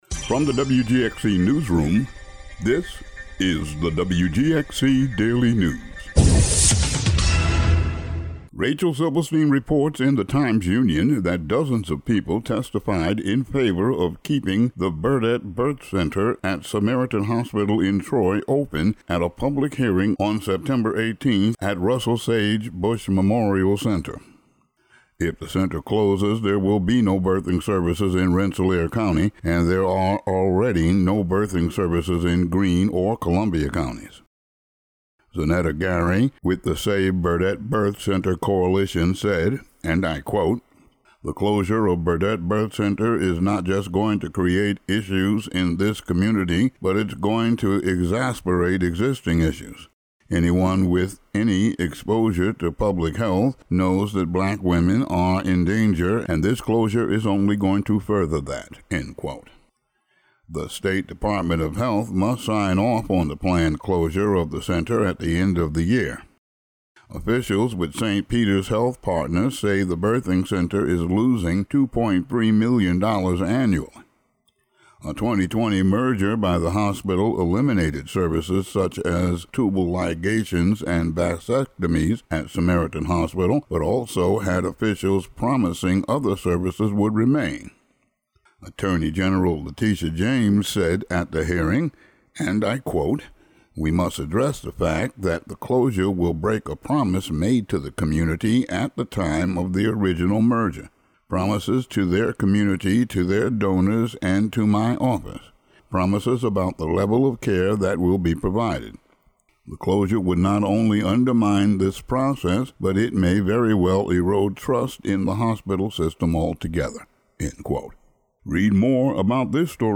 Today's daily local audio news.